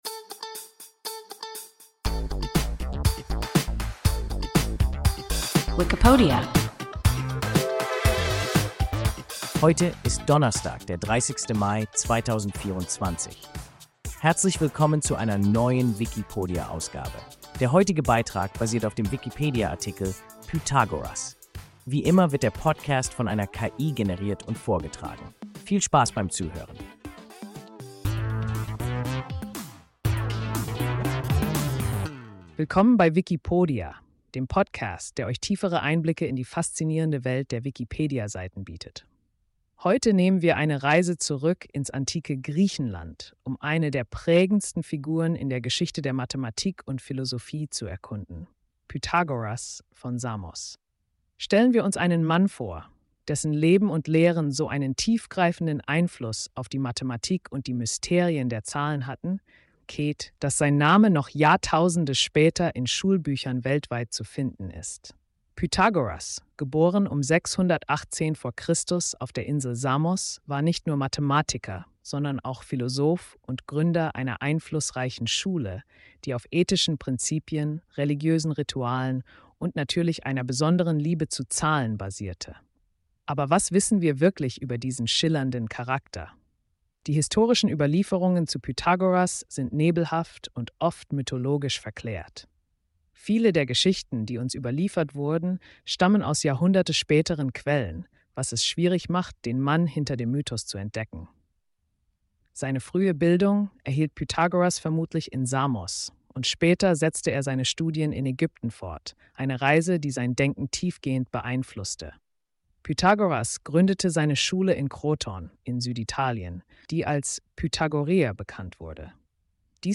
Pythagoras – WIKIPODIA – ein KI Podcast